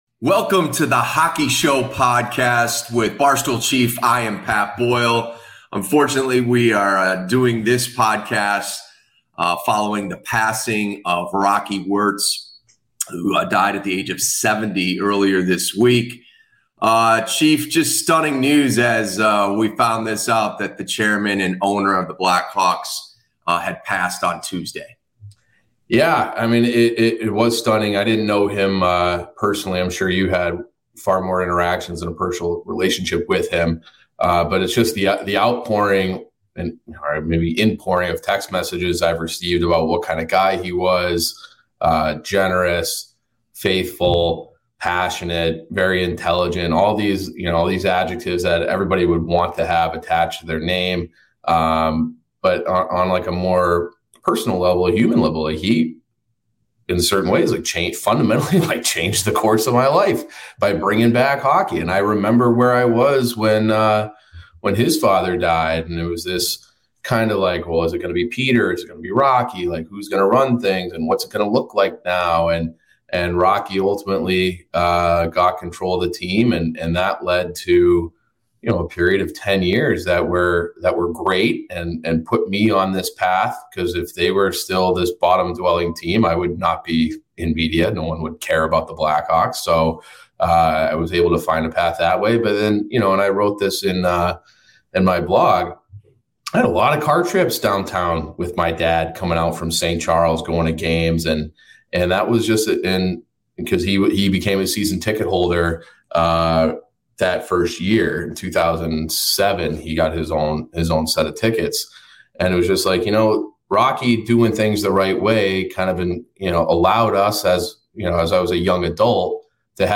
In this heartfelt discussion, the hosts shed light on Rocky Wirtz's leadership style, his unyielding support for the team and its fans, and how he fostered a sense of community around the sport.